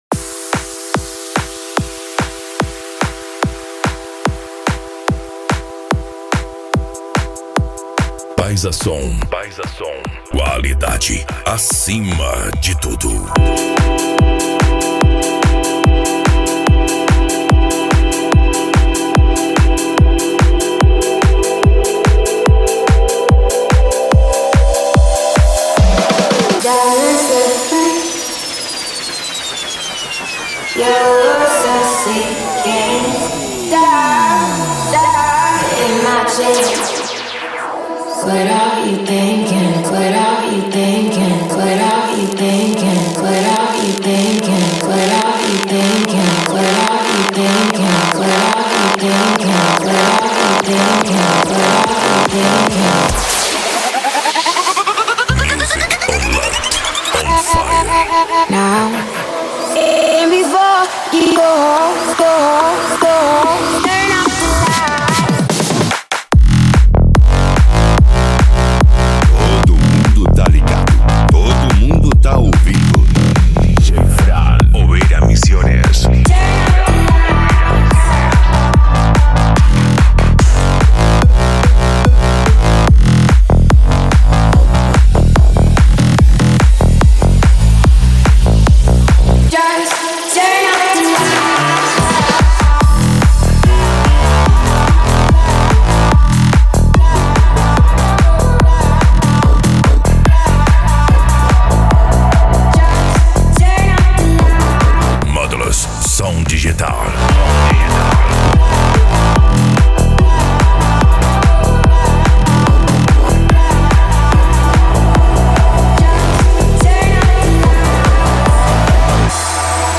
Psy Trance
Racha De Som
Remix